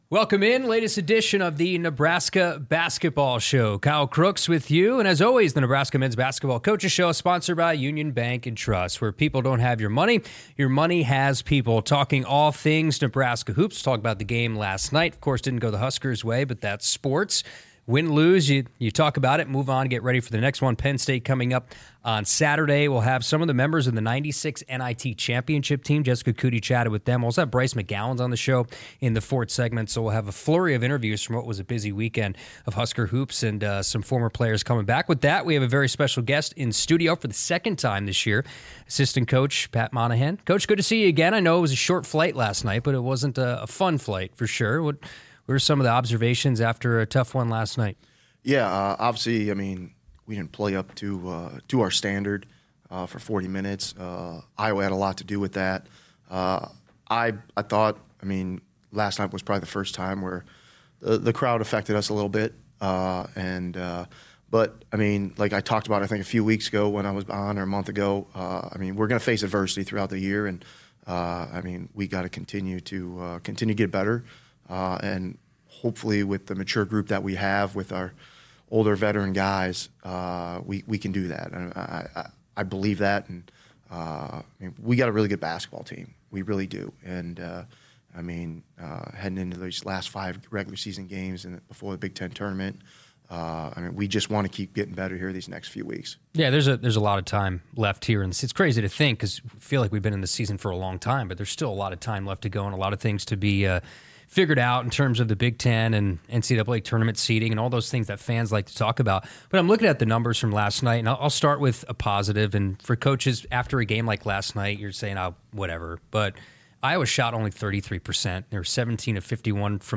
Alumni Weekend Interviews